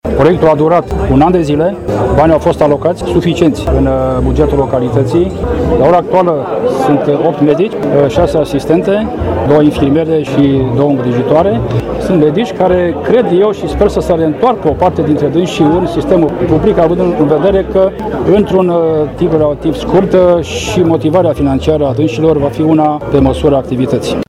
La rândul său, primarul municipiului Săcele, Virgil Popa, a prezentat sintetic organigrama spitalului, apreciind că sunt șanse ca aceasta să fie completată în viitorul apropiat: